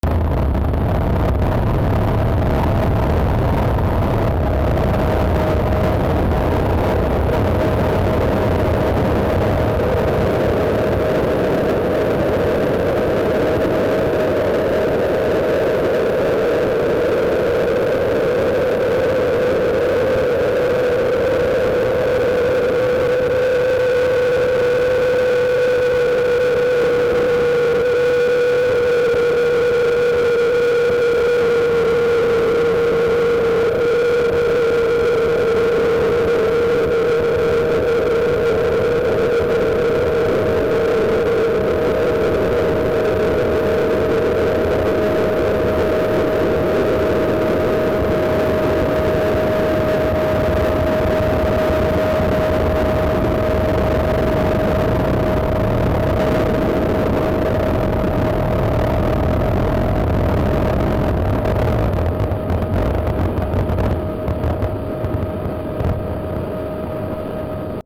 Noise that plays whenever A-60 spawns in the game.